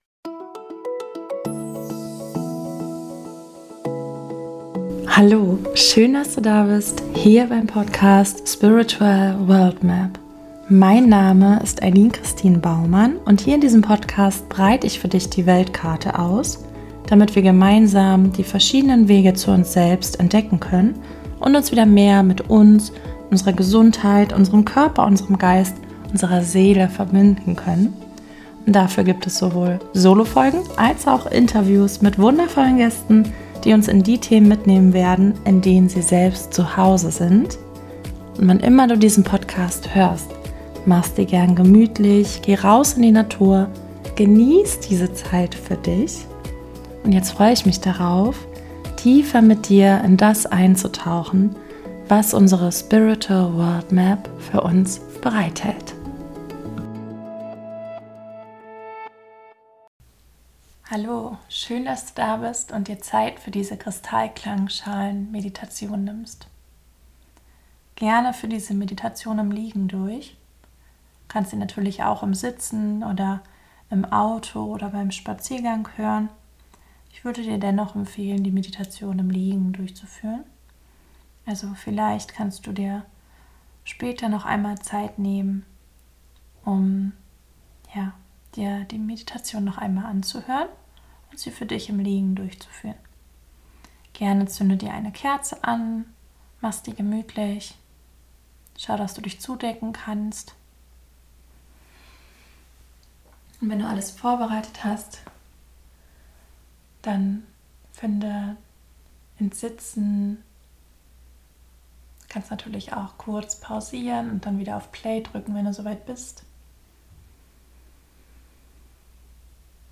Beschreibung vor 3 Monaten In der heutigen Podcast Folge erwartet dich eine kurze Sound Meditation mit Kristall Klangschalen (Crystal Bowls). Gern höre die Klänge auch einmal im Liegen und mal in der Natur.
Selbstverständlich haben die Klänge vor Ort eine intensivere Wirkung, als im Podcast.